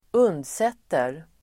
Uttal: [²'un:dset:er]